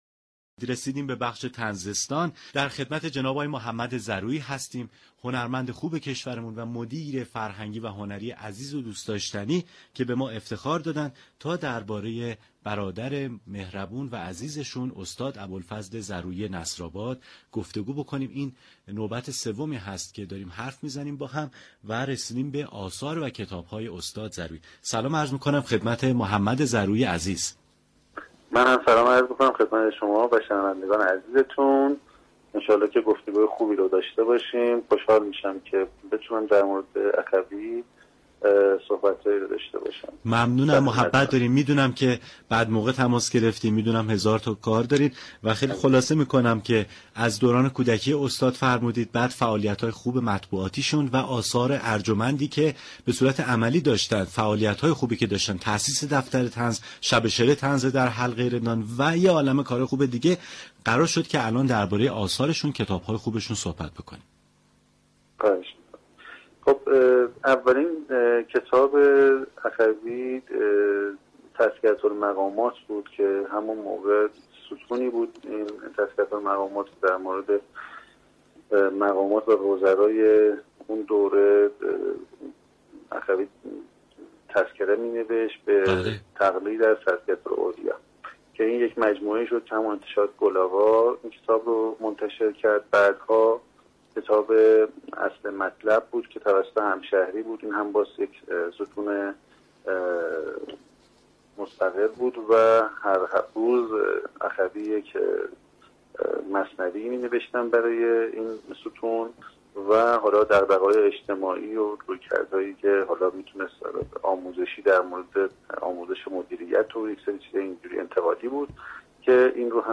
گفتگوی تلفنی